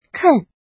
怎么读
kèn
ken4.mp3